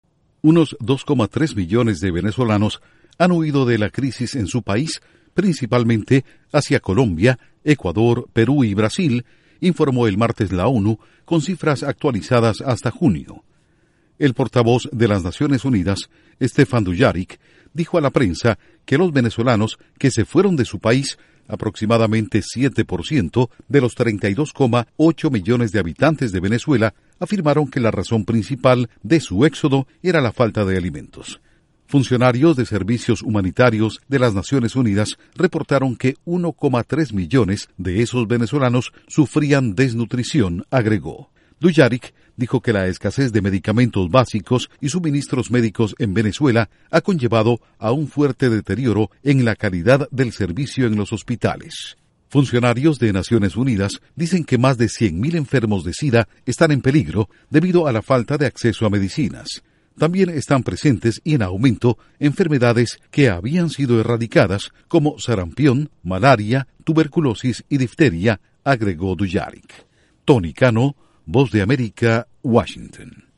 ONU: La falta de alimentos empuja a 2,3 millones a huir de Venezuela. Informa desde la Voz de América en Washington